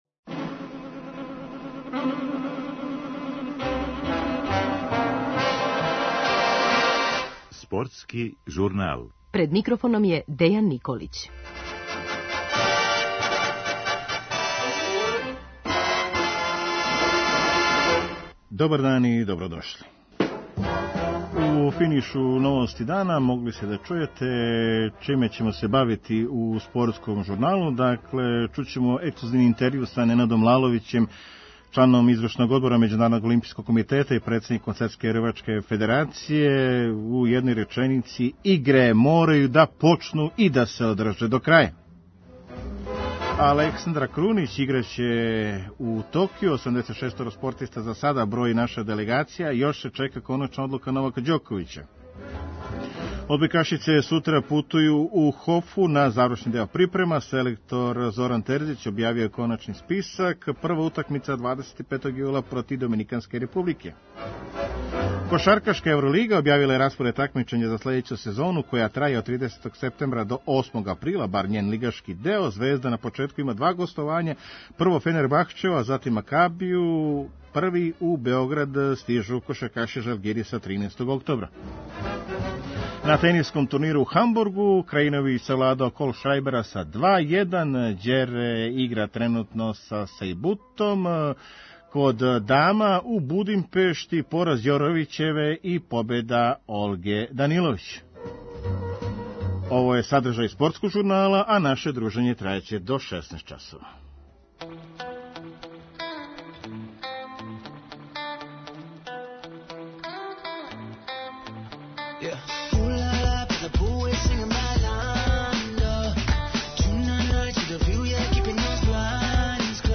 Ексклузивни гост Спортског журнала Првог програма Радио Београда је члан Међународног олимпијског комитета, члан Извршног одбора МОК-а и председник Светске рвачке федерације Ненад Лаловић који сутра путује у Токио где ће се од 23. јула до 8. августа одржати Олимпијске игре.